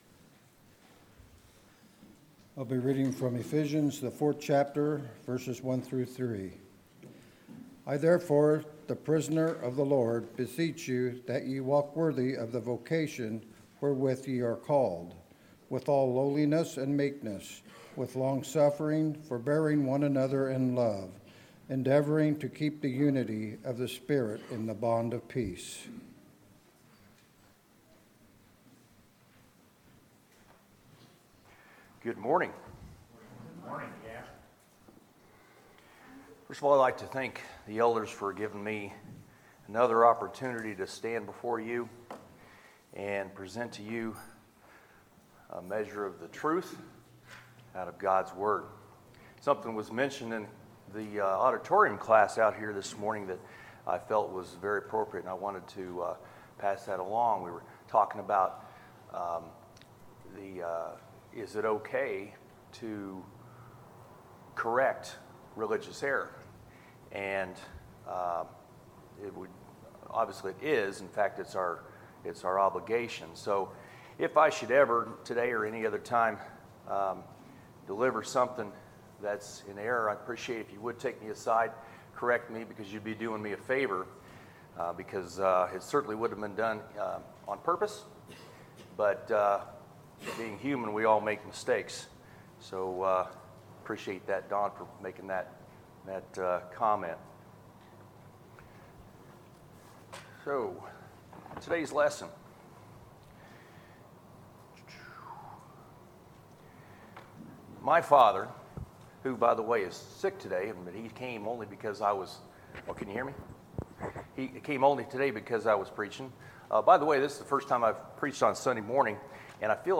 Sermons, August 19, 2018